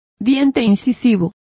Complete with pronunciation of the translation of incisor.